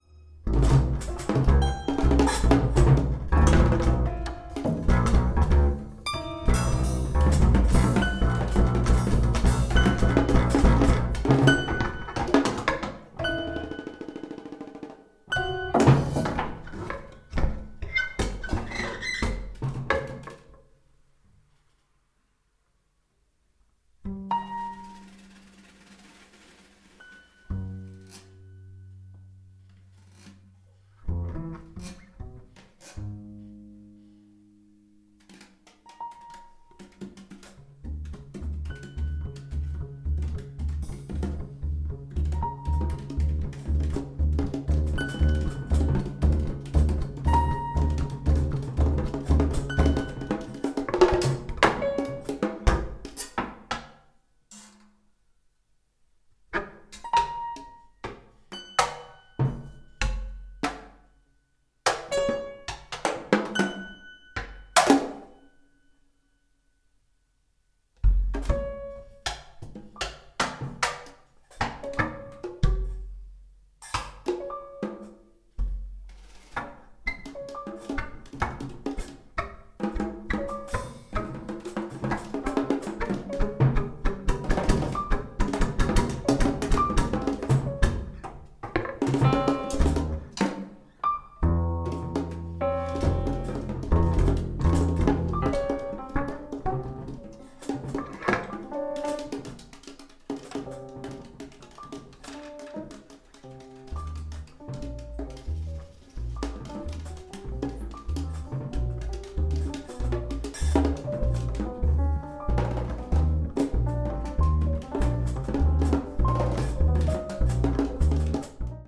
トリオ編成のライブ録音!!
dr & perc
live concert
free and structured improvisations